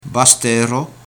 Prononcer entre "baste" et "basto".